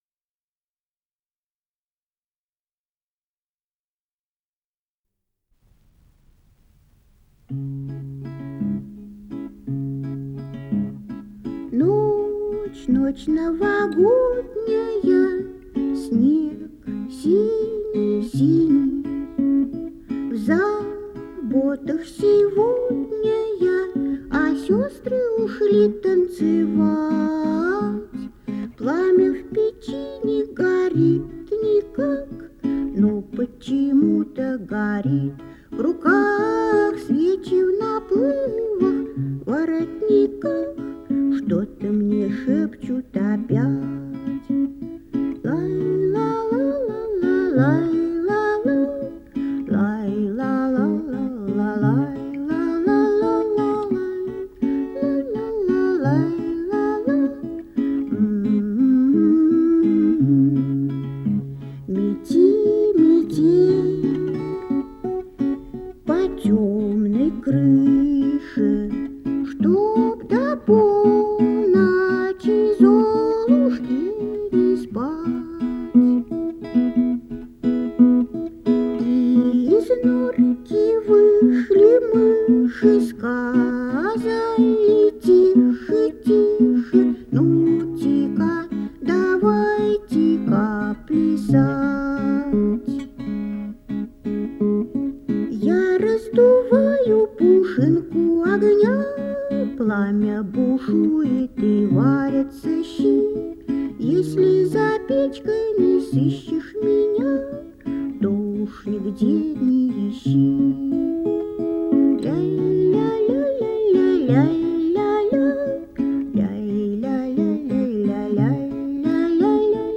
с профессиональной магнитной ленты
РедакцияМузыкальная
пение в собственном сопровождении на гитаре